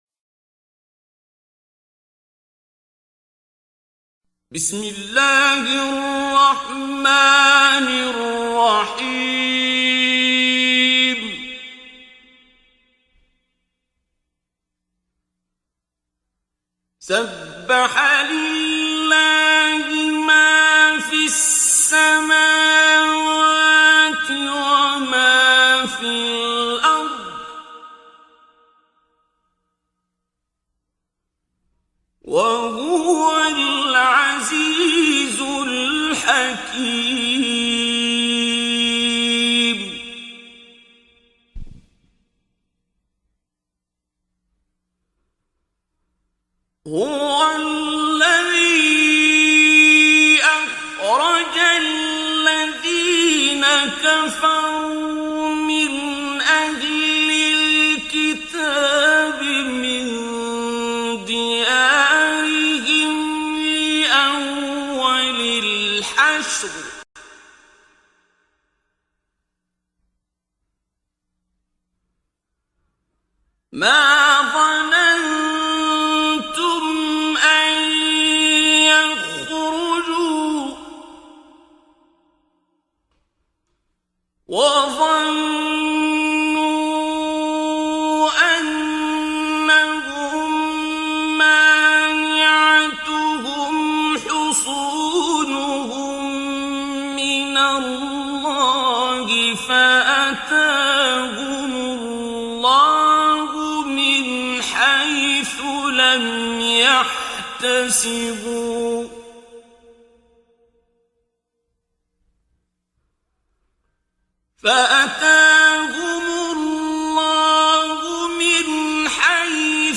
دانلود تلاوت زیبای سوره حشر آیات 1 الی 24 با صدای دلنشین شیخ عبدالباسط عبدالصمد
در این بخش از ضیاءالصالحین، تلاوت زیبای آیات 1 الی 24 سوره مبارکه حشر را با صدای دلنشین استاد شیخ عبدالباسط عبدالصمد به مدت 23 دقیقه با علاقه مندان به اشتراک می گذاریم.